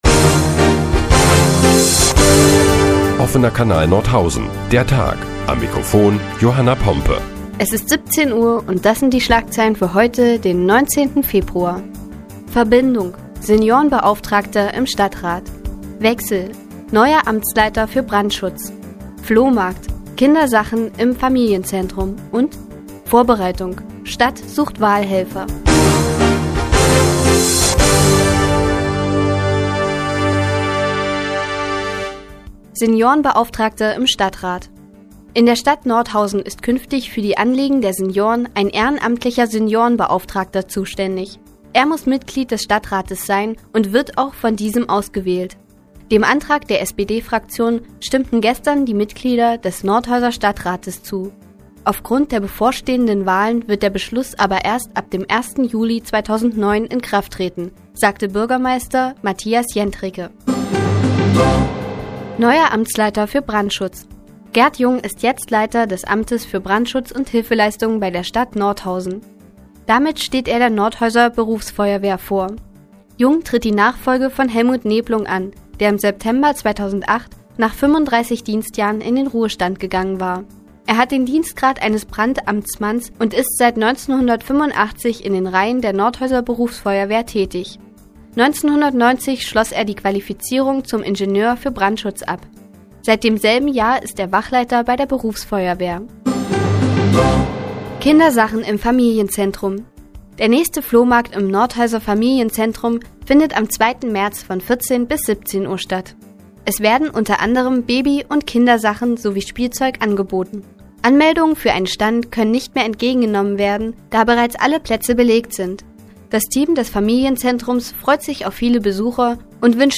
Die tägliche Nachrichtensendung des OKN ist nun auch in der nnz zu hören. Heute geht es unter anderem um den neuen Amtsleiter für Brandschutz und Kindersachen im Familienzentrum.